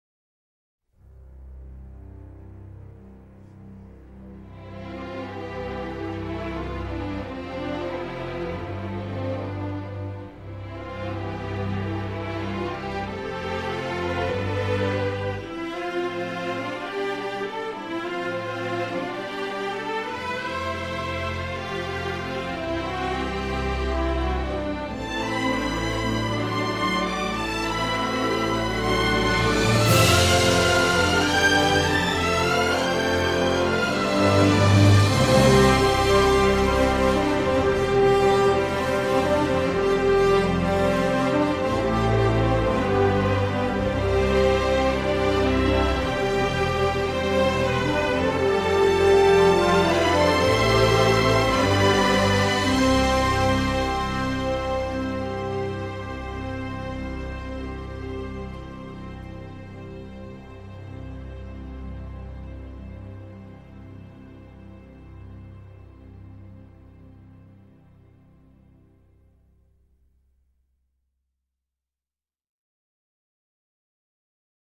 est un score symphonique inspiré
Une grande et belle partition.